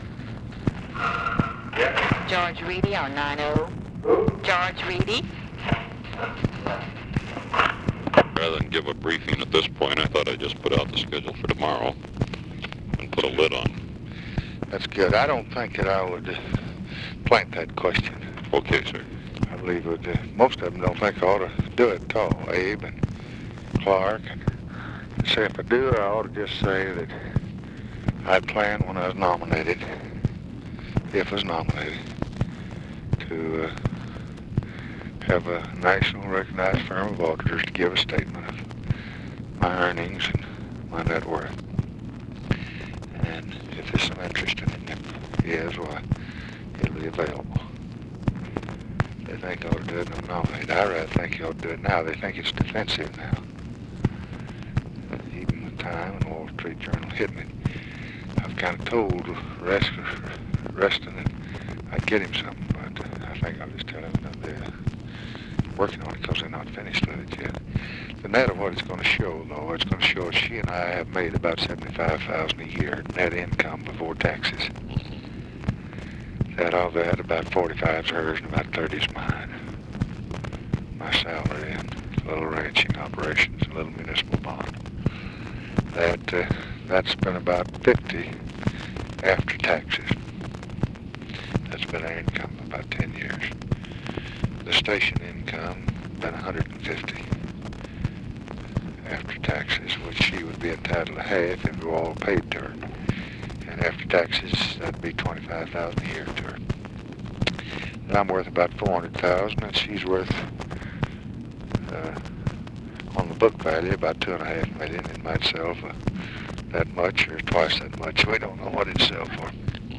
Conversation with GEORGE REEDY, August 12, 1964
Secret White House Tapes